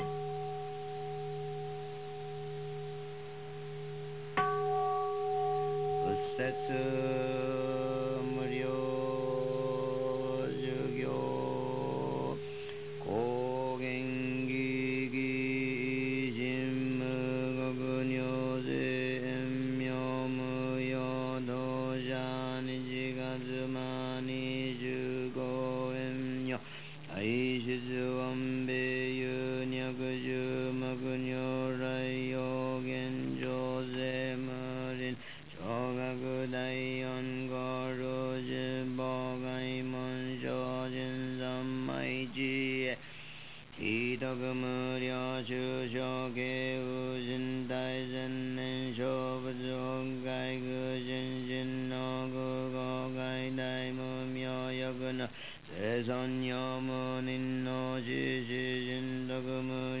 SAMBUTSUGUE (Hino de Louvor ao Buda)